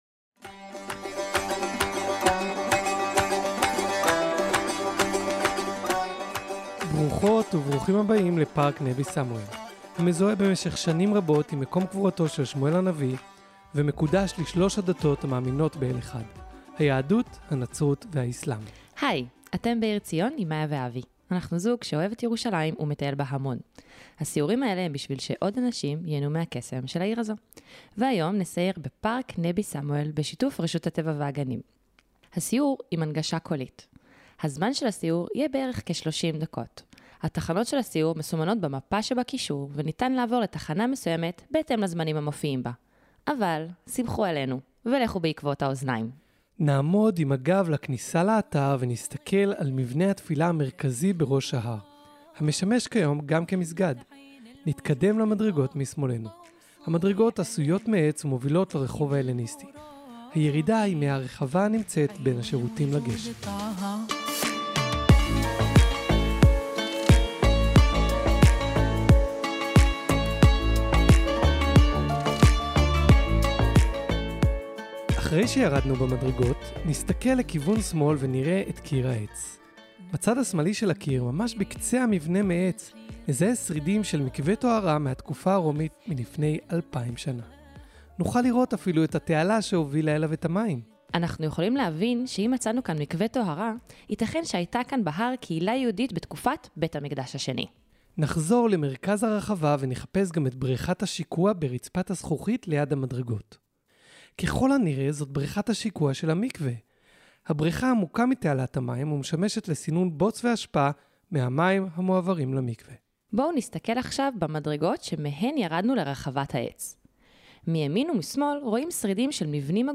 מדריך קולי בפארק נבי סמואל
מדריך קולי Trip-Talk המשמש כמדריך מלווה למבקר העצמאי, ומזמין לטייל בצורה חווייתית באתר.